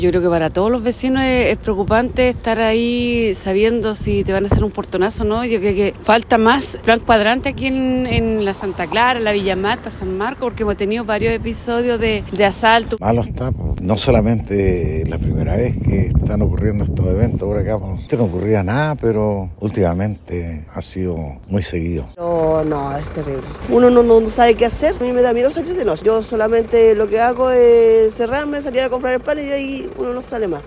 En conversación con Radio Bío Bío, los vecinos manifestaron su preocupación por el alza en episodios de delincuencia, pidiendo mayor presencia policial.